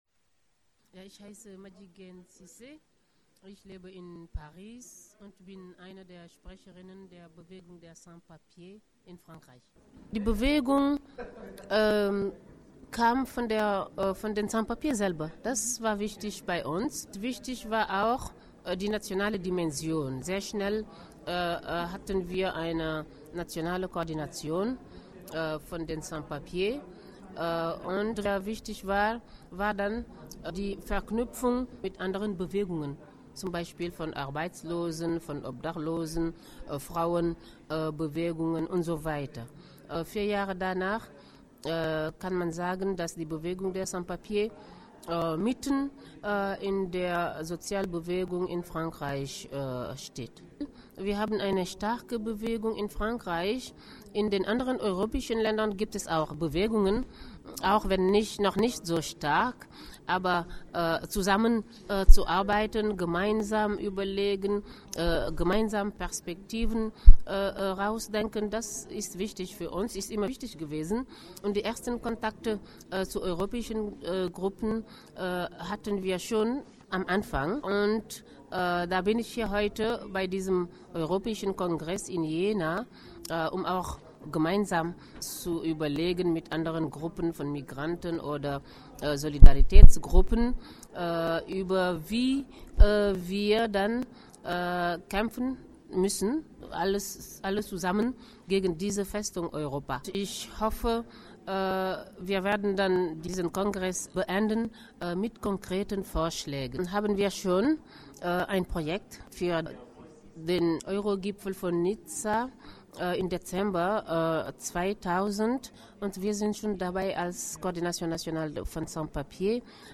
Interview zu den Sans Papiers